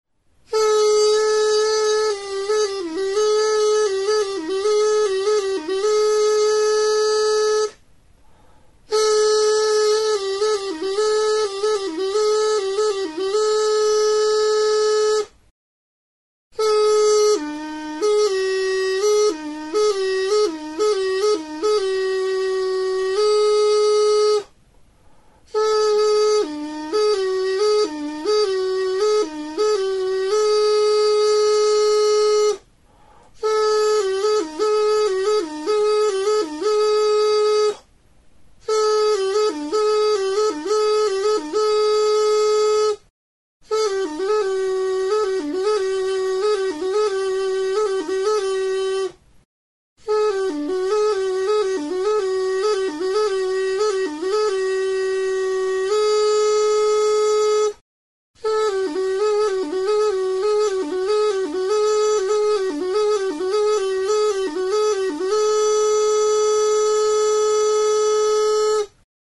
Aerophones -> Reeds -> Double (oboe)
Recorded with this music instrument.
TURUTA; OLO-GARI ZUZTARRA
Gari-olo zuztarraren ordez, edateko plastikozko lastotxo batekin egindako turuta. Doinuak osatzeko 2 zulo ditu.